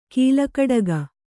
♪ kīlakaḍaga